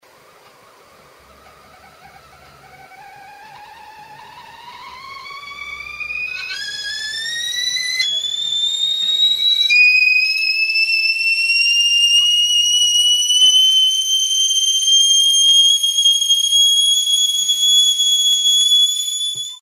Звуки электрического чайника
На этой странице собраны звуки электрического чайника: от включения до характерного щелчка при завершении кипячения.
Чайник кипит и издает свист